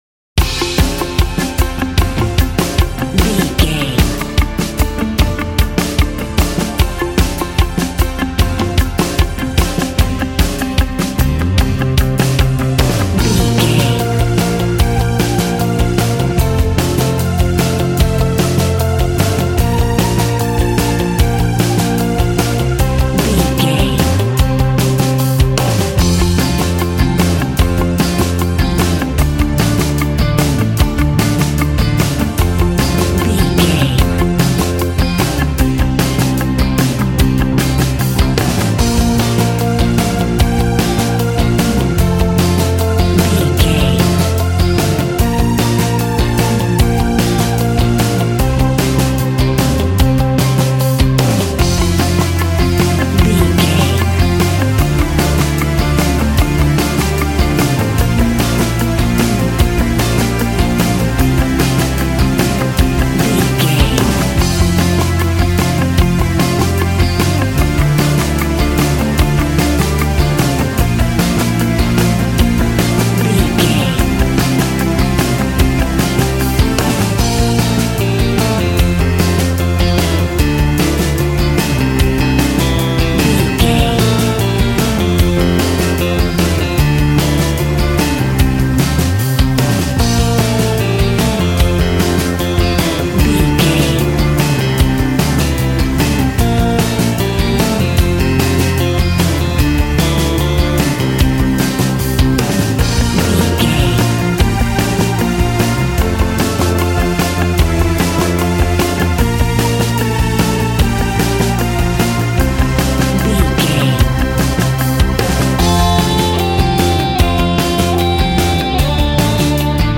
Ionian/Major
groovy
powerful
fun
organ
drums
bass guitar
electric guitar
piano